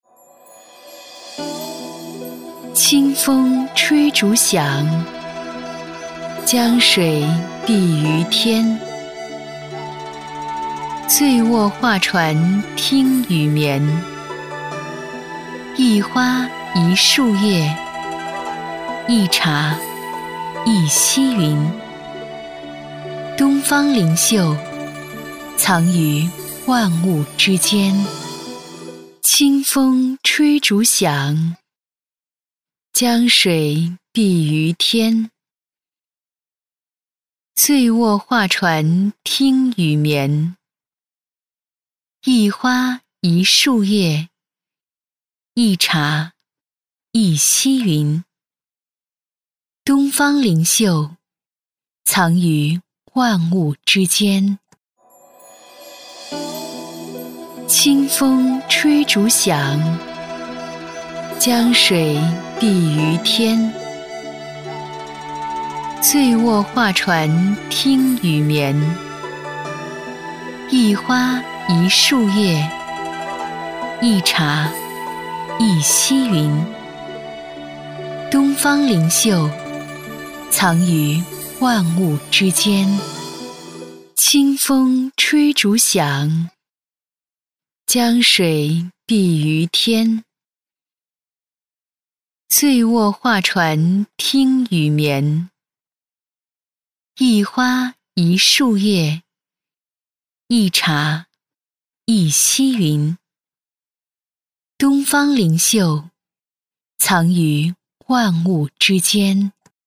美式英语青年亲切甜美 、女旅游导览 、200元/分钟女S112 地铁报站播报 中文 英文双语报站 亲切甜美